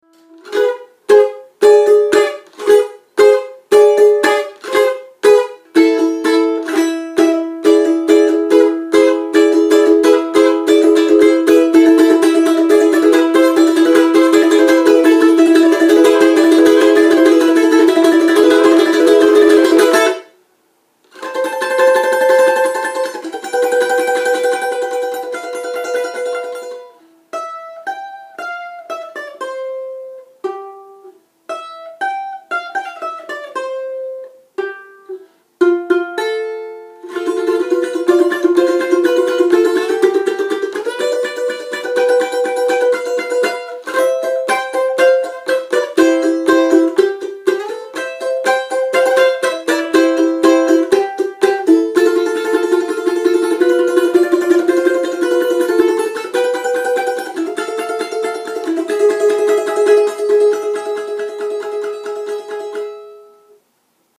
Traditional Russian Instrument
Balalaika
Audio file of the "Balalaika"
Balalaika.mp3